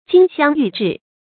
金相玉質 注音： ㄐㄧㄣ ㄒㄧㄤˋ ㄧㄩˋ ㄓㄧˋ 讀音讀法： 意思解釋： 比喻文章的形式和內容都完美。